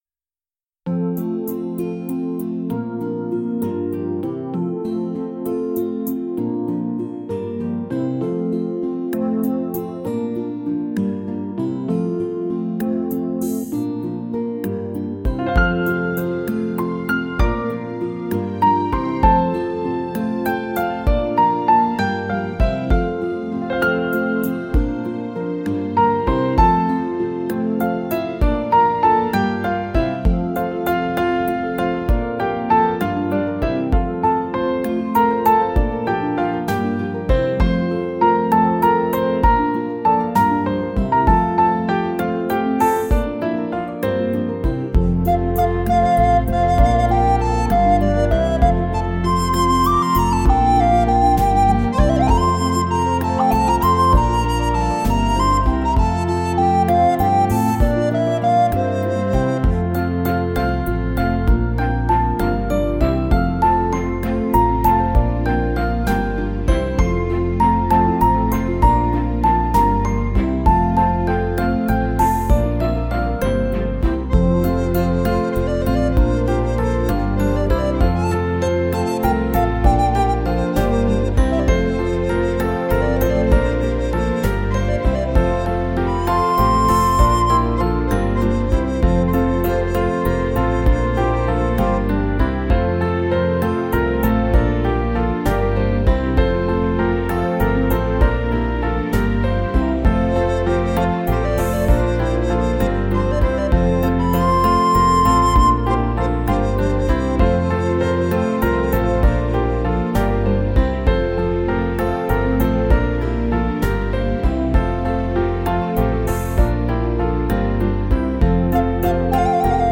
Die schönsten Kanon-Kompositionen zum Meditieren
Der Kanon ist eine ganz besondere musikalische Gattung.